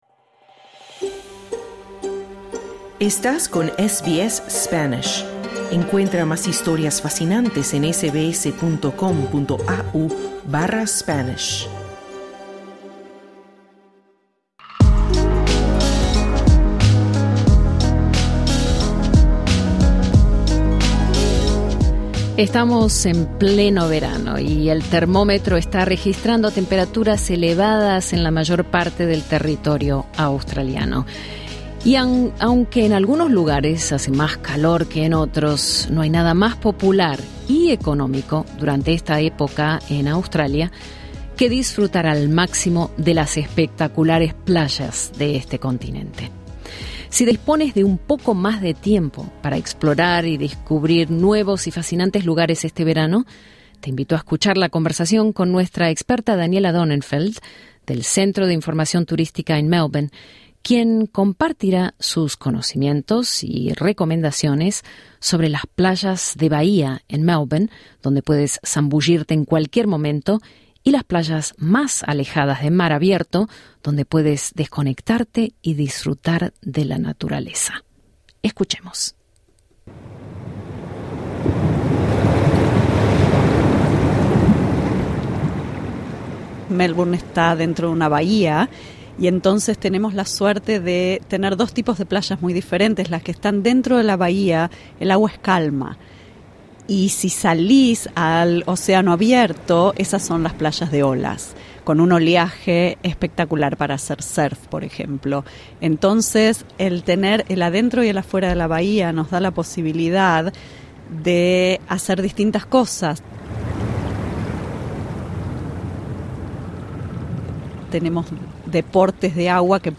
Conversamos con la experta en turismo